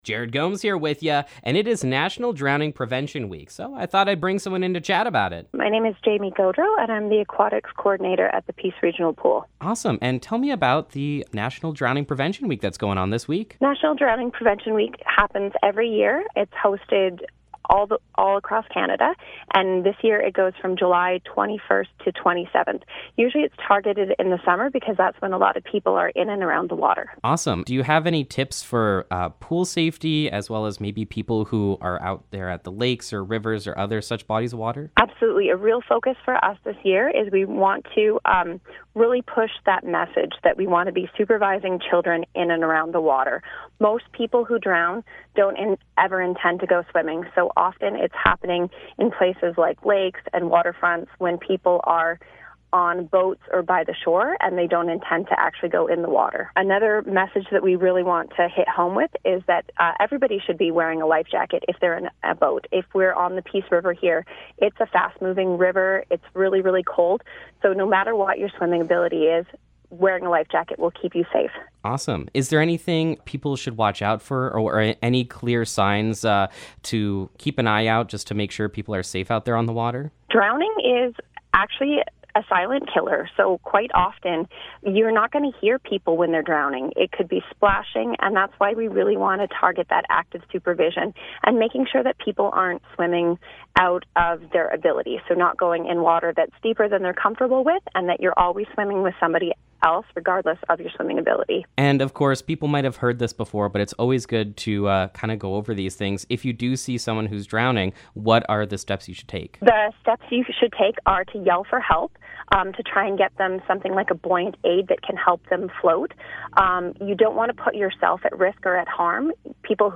National Drowning Prevention Week Interview
drowning-prevention-interview-final.mp3